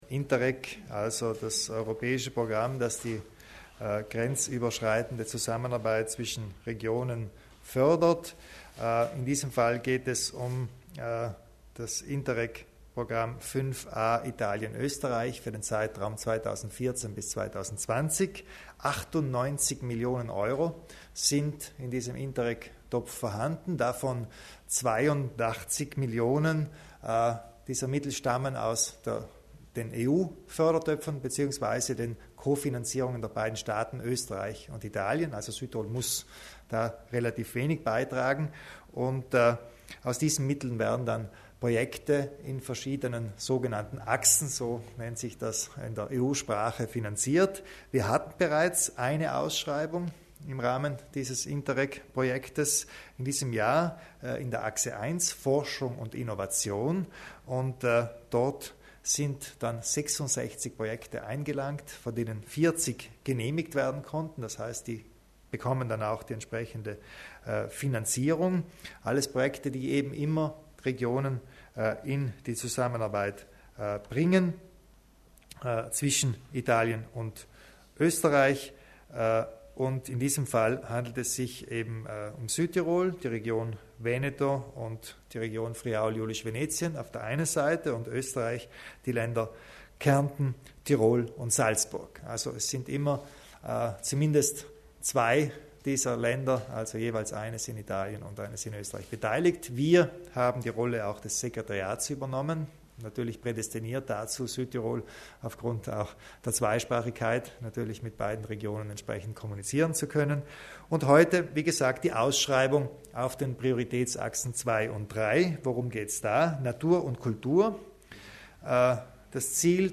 Landeshauptmann Kompatscher zu den Interreg-Programmen